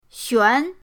xuan2.mp3